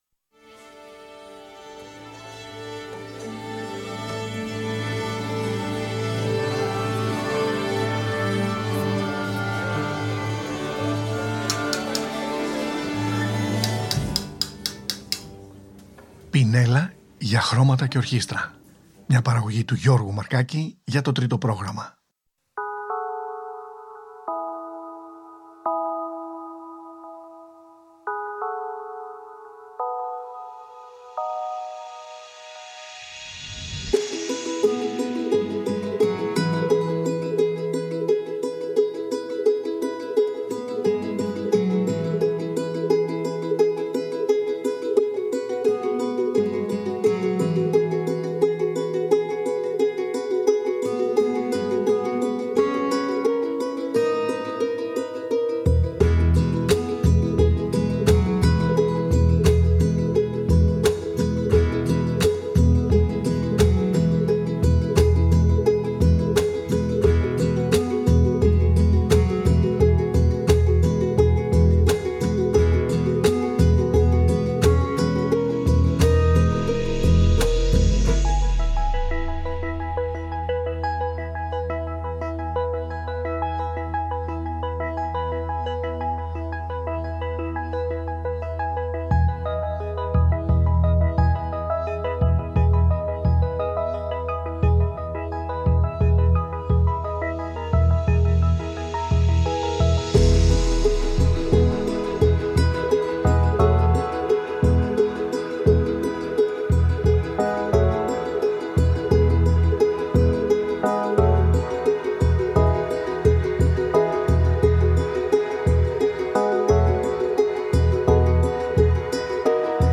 Μουσική